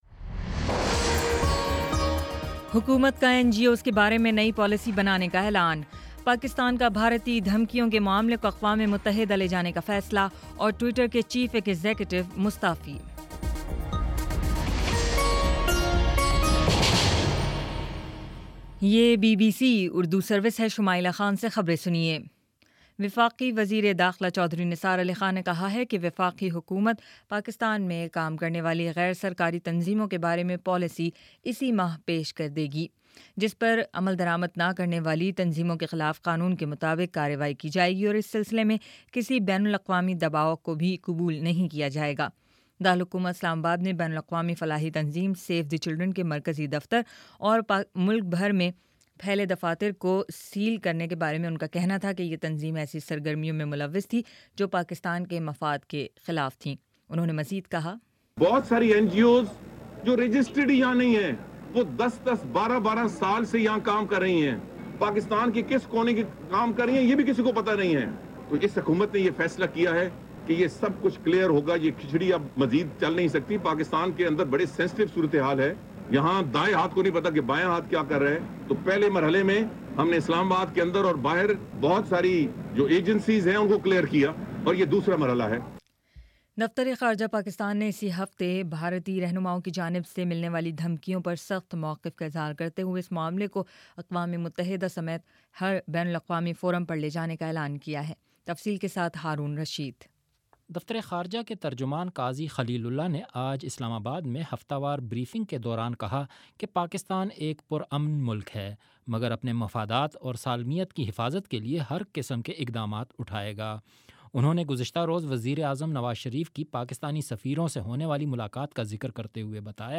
جون 12: شام چھ بجے کا نیوز بُلیٹن